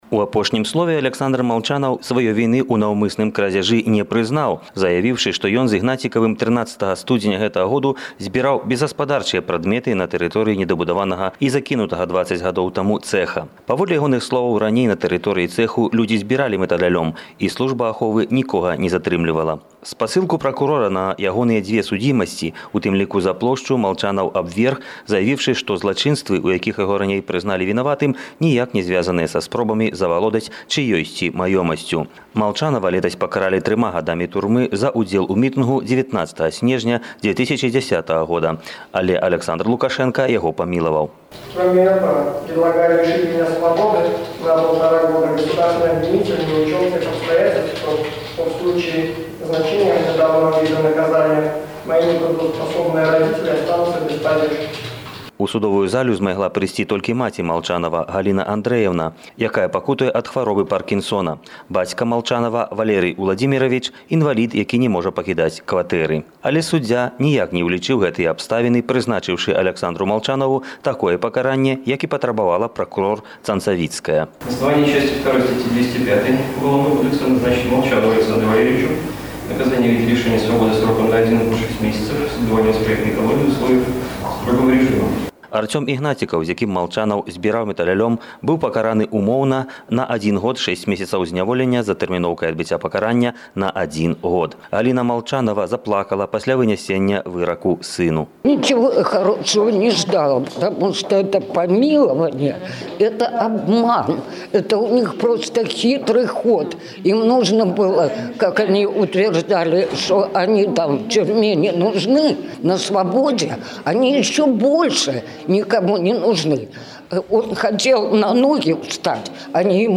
Рэпартаж
Жодзіна, 9 красавіка 2012